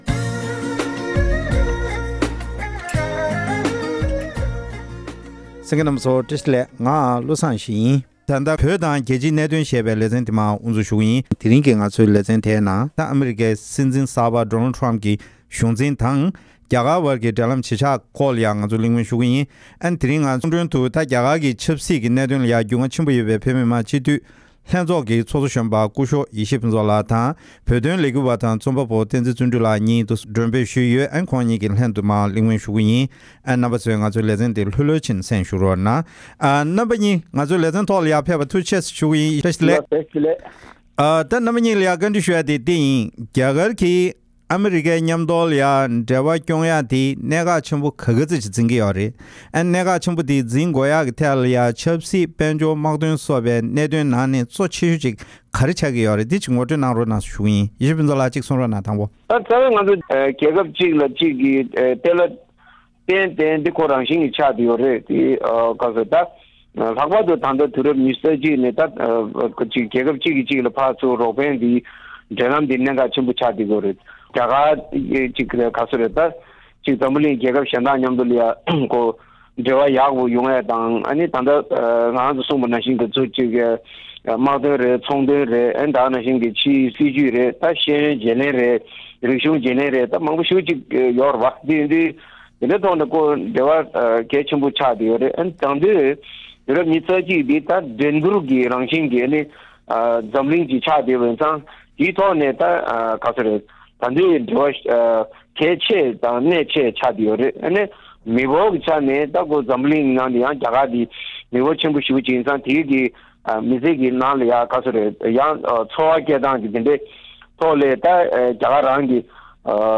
ཨ་མི་རི་ཀའི་གཞུང་འཛིན་གསར་པ་དང་རྒྱ་གར་བར་གྱི་འབྲེལ་ལམ་ཇི་ཆགས་དང་དེའི་ཤུགས་རྐྱེན་བཅས་ཀྱི་སྐོར་བགྲོ་གླེང༌།